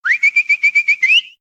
call_pet.ogg